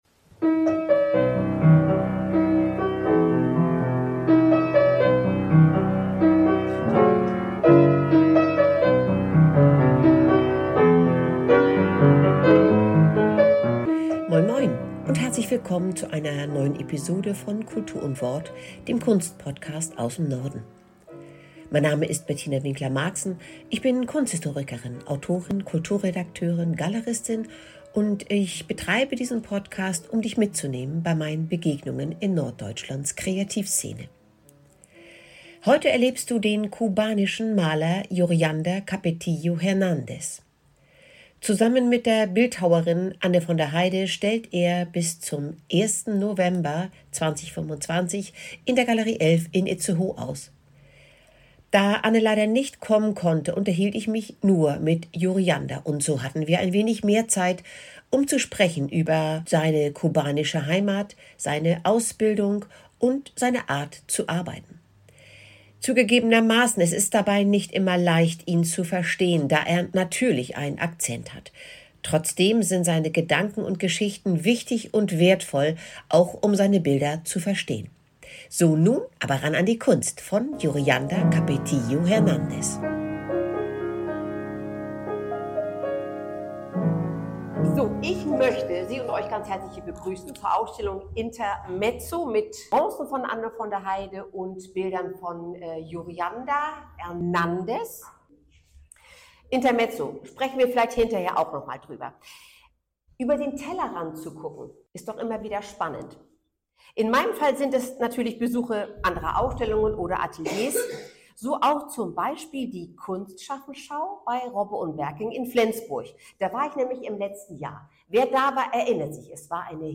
Wir haben Zeit, um zu sprechen über seine kubanische Heimat, seine Ausbildung und seine Art zu arbeiten. Es ist dabei nicht immer leicht, ihn zu verstehen, da er natürlich einen Akzent hat.